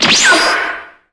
SFX item_armor_break_2.wav